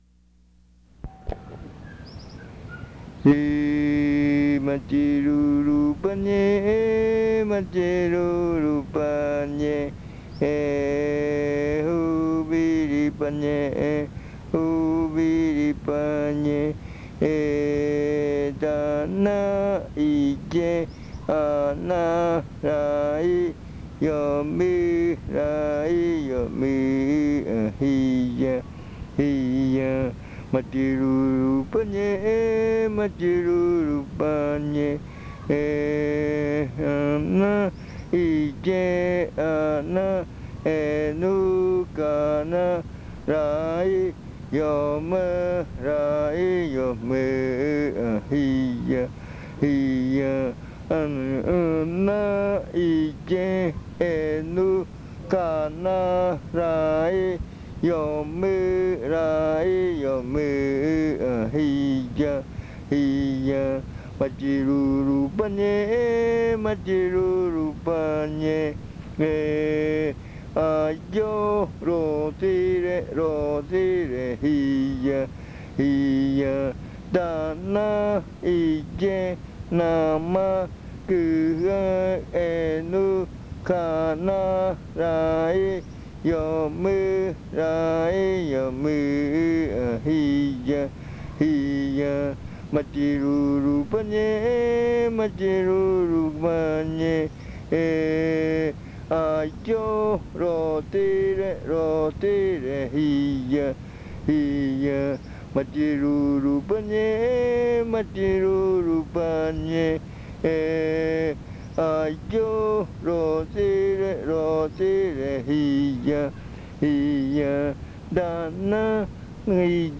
Leticia, Amazonas
7:35-10:35 PM. canción que pertenece a Baile de culebra.
Chant that belongs to ritual of the snake.
This chant is part of the collection of chants from the Yuakɨ Murui-Muina (fruit ritual) of the Murui people